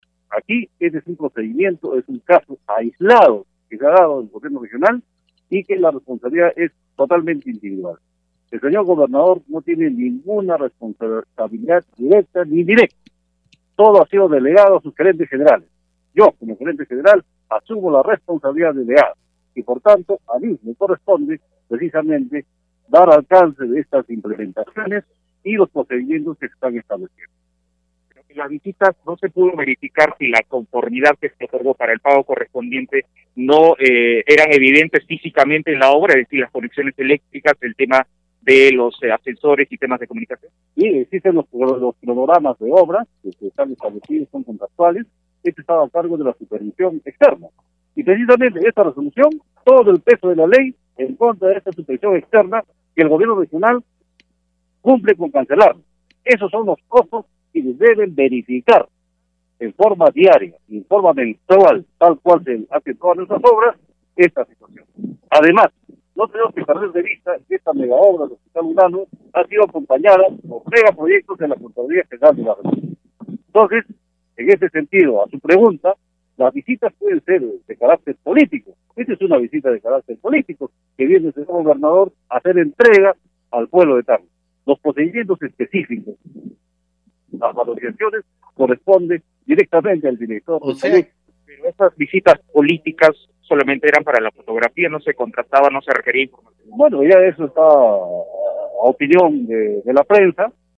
El gobernador regional de Tacna reapareció ante los medios de comunicación la mañana de hoy 2 de febrero durante una entrega de ambulancias al sector salud.